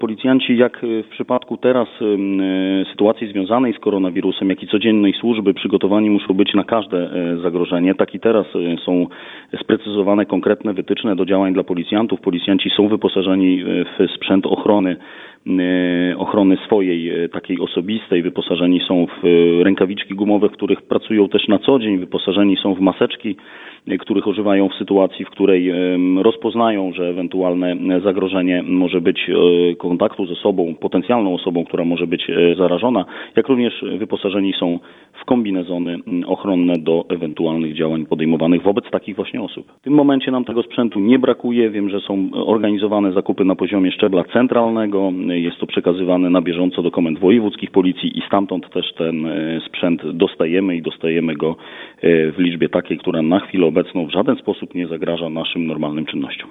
Policjanci od początku marca zaczęli raz na dobę odwiedzać osoby, które podlegały domowej kwarantannie. Suwalska Policja jest przygotowana na koronawirusa – zapewniał Bartosz Lorenc, Komendant Miejski Policji w Suwałkach.